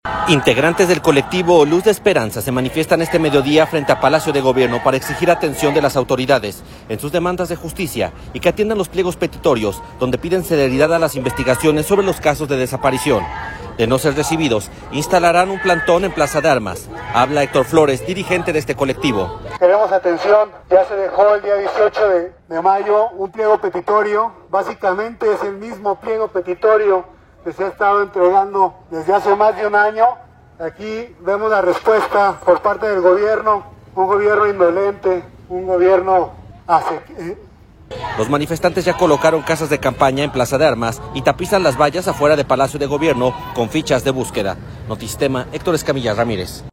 Integrantes del colectivo Luz de Esperanza se manifiestan este mediodía frente a Palacio de Gobierno para exigir atención de las autoridades en sus demandas de justicia y que atiendan los pliegos petitorios donde piden celeridad a las investigaciones sobre los casos de desaparición. De no ser recibidos instalarán un plantón en Plaza de Armas.